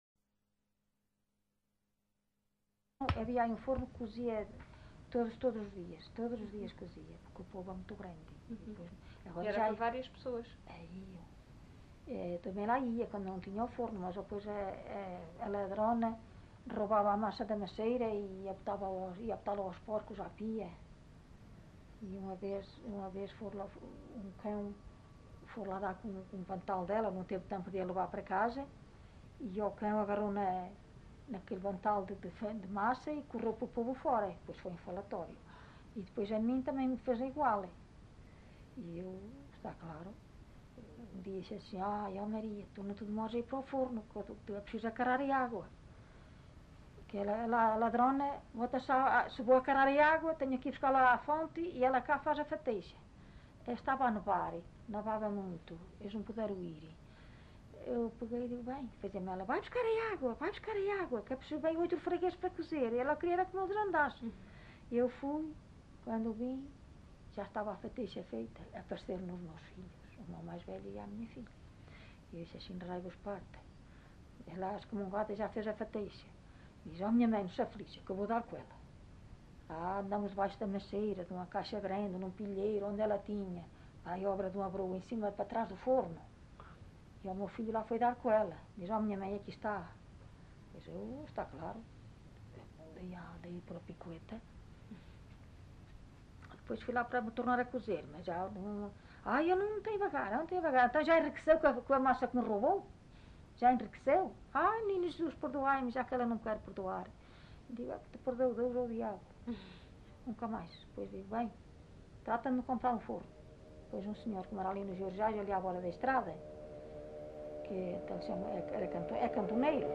LocalidadePerafita (Alijó, Vila Real)